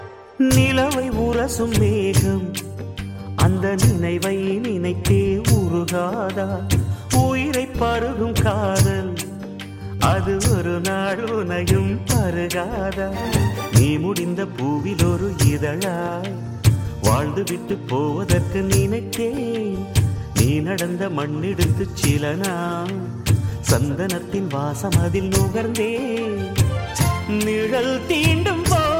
best flute ringtone download | love song ringtone